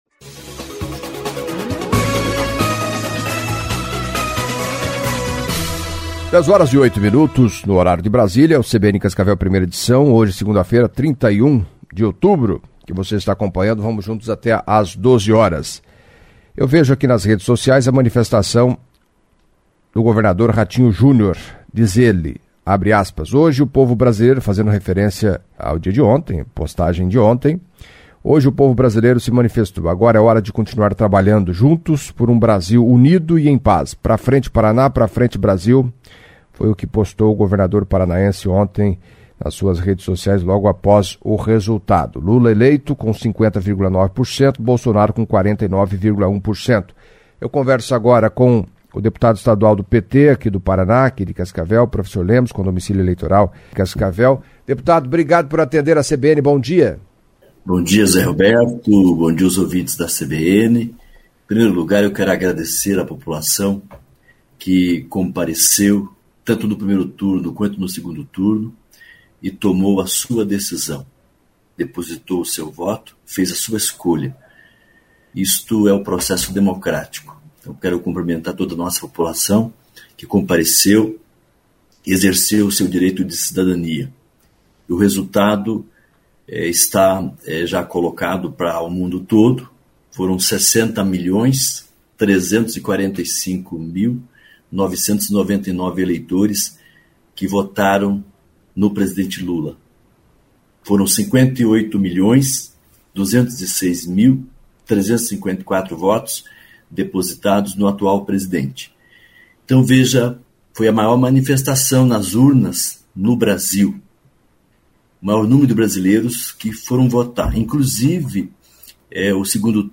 Em entrevista à CBN Cascavel nesta segunda-feira (31) o deputado estadual Professor Lemos, do PT, entre outros assuntos, falou da vitória apertada do candidato Lula no 2º turno, o que espera da relação de Lula com o Congresso, a posição do Paraná que deu larga vantagem de votos para Bolsonaro, ameaça de ocupações de terra pelo MST no Paraná e respondeu perguntas de ouvintes.